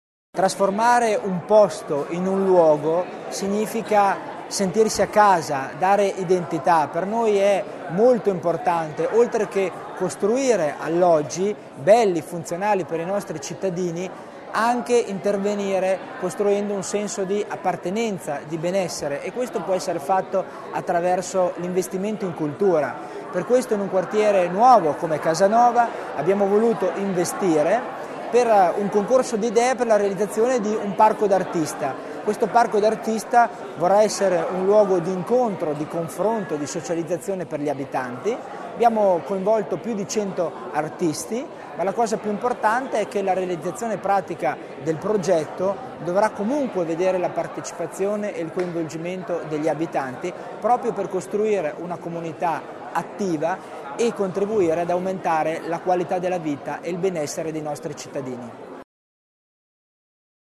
L'Assessore Tommasini sull'importanza del progetto